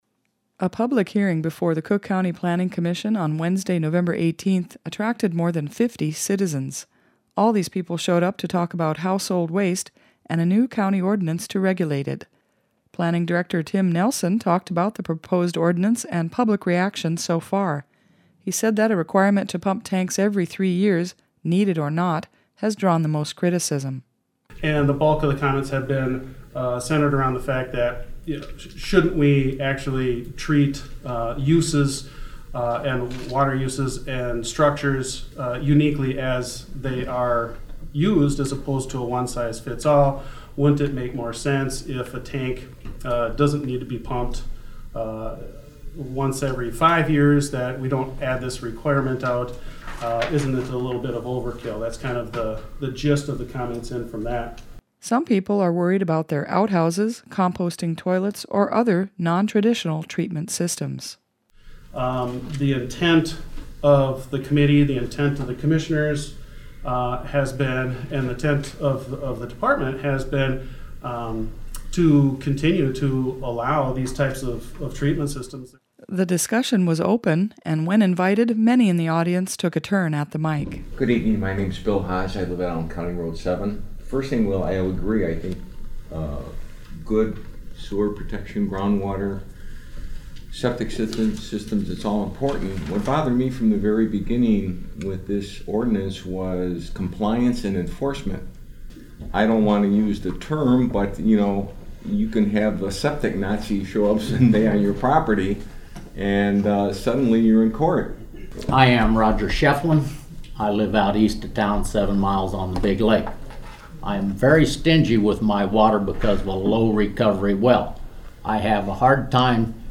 On Wednesday, Nov. 18, the Cook County Planning Commission conducted its first public hearing on the ordinance.  More than 50 people attended the nearly two hour session.